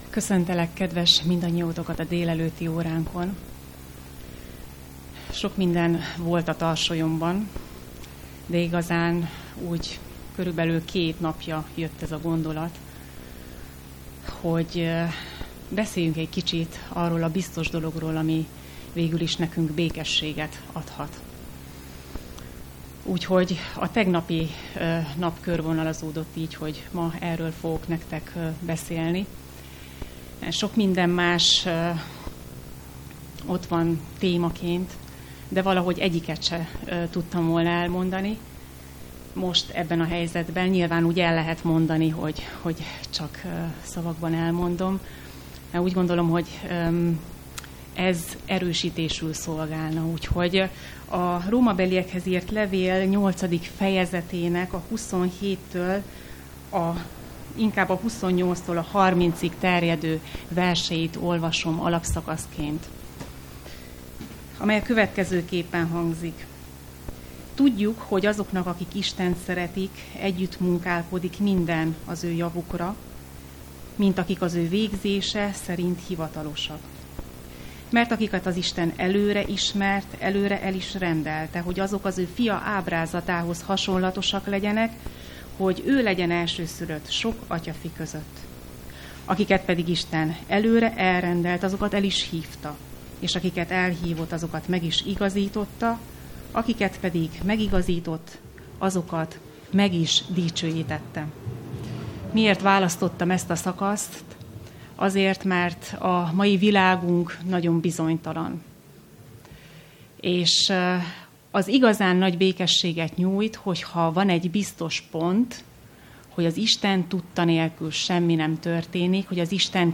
Szombati igehirdetés